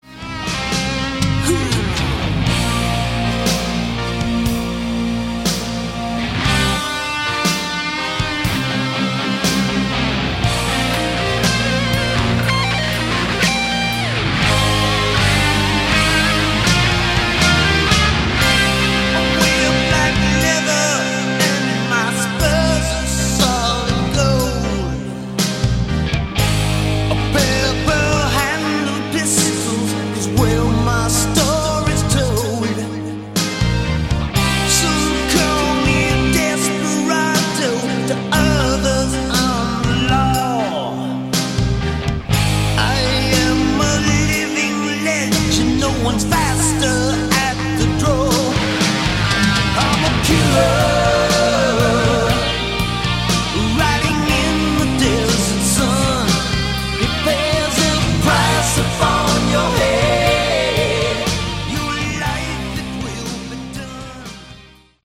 Category: Hard Rock
drums, percussion
lead guitar
bass, backing vocals
lead vocals, rthythm guitar
keyboards, backing vocals